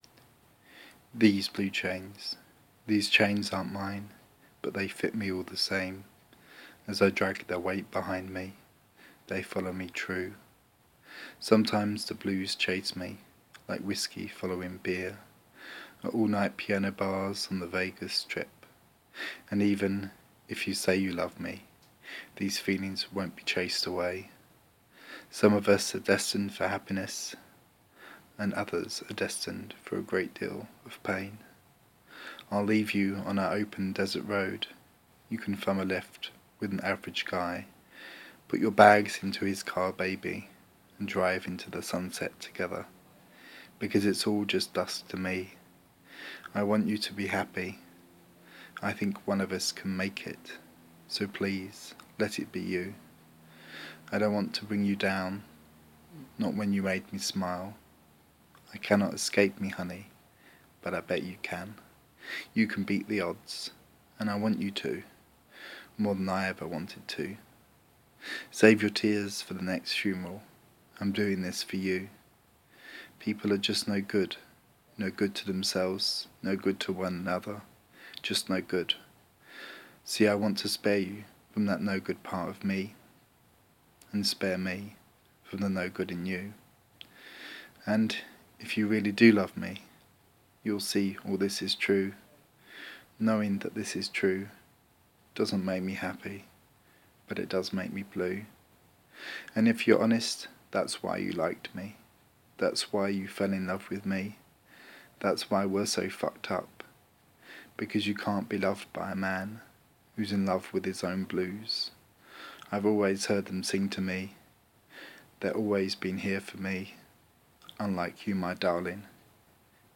A spoken word performance of a blues song